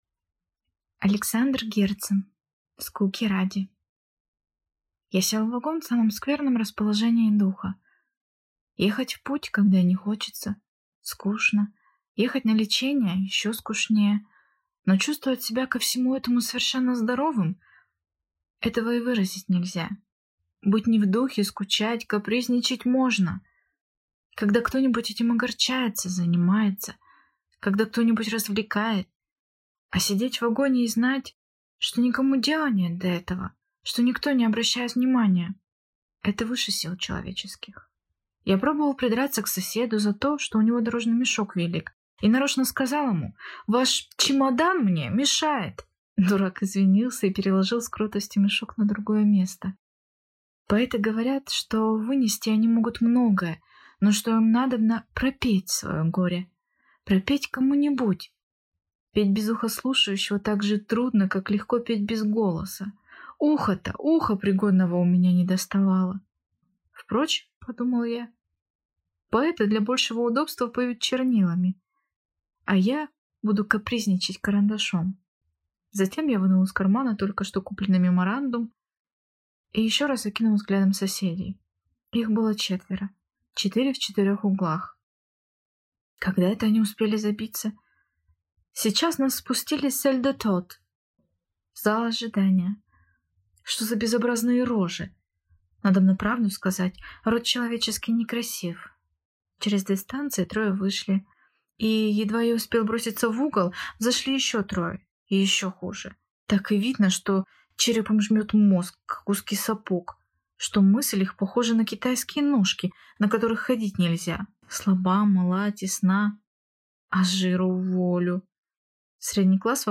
Аудиокнига Скуки ради | Библиотека аудиокниг